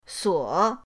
suo3.mp3